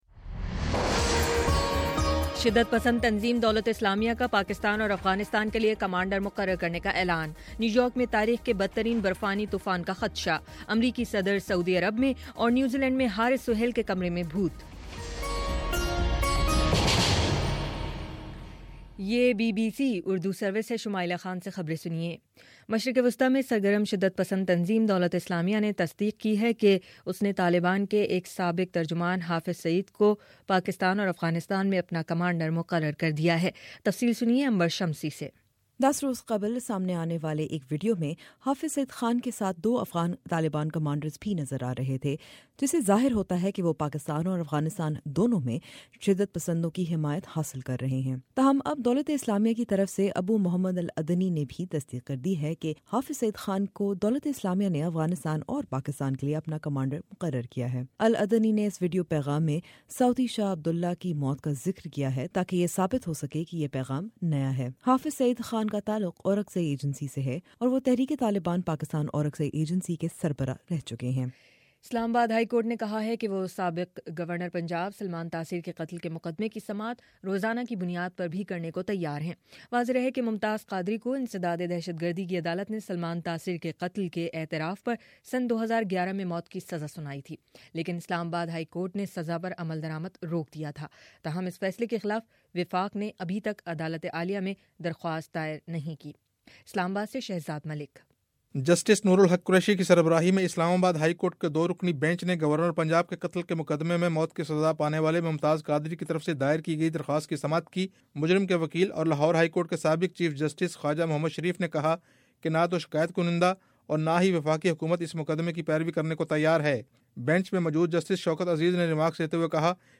جنوری 27: شام سات بجے کا نیوز بُلیٹن